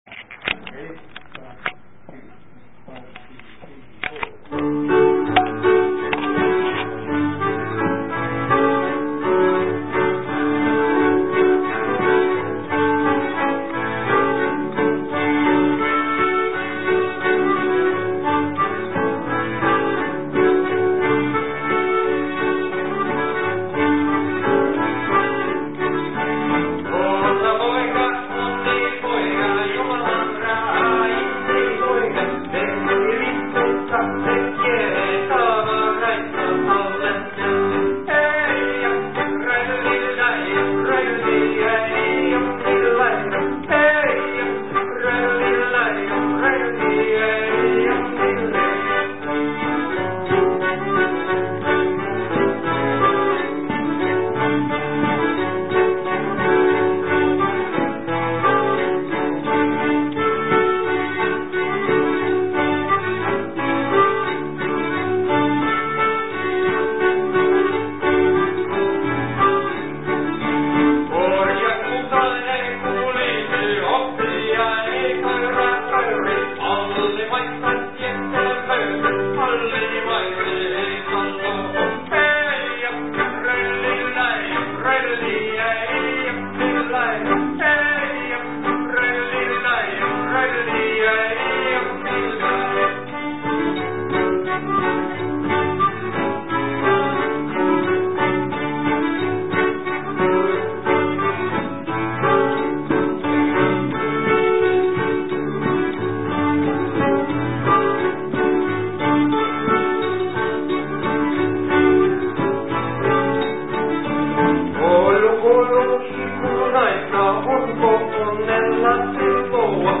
Solo
above - a large audience came to enjoy the evening of music and comedy